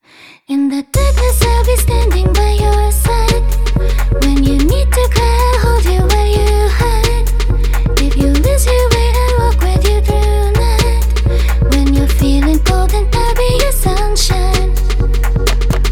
IVIIVIIVIIImIImVI
4-1という穏やかなSTのモーションを2回繰り返してから、3回目はメロディは全く同じですがコードは4-3と変化がついて、最後は2-5-1という定番のSDTの動きで終わります。